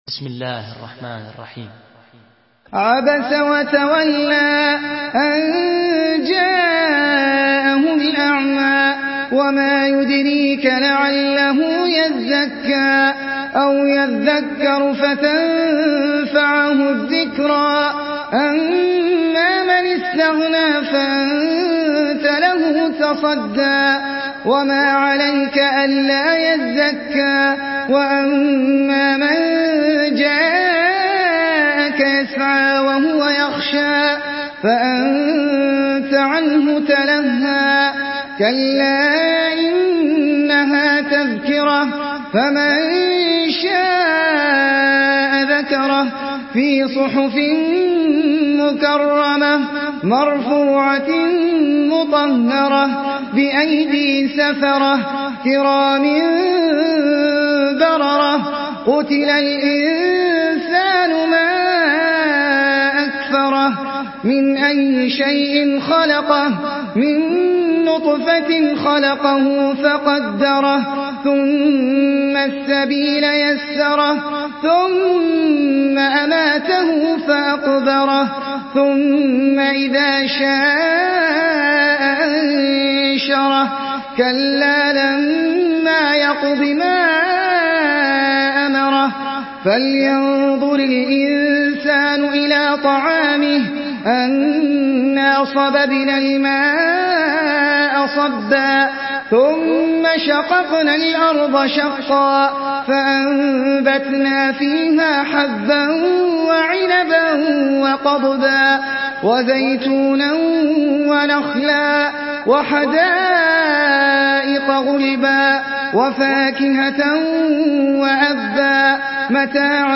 سورة عبس MP3 بصوت أحمد العجمي برواية حفص
مرتل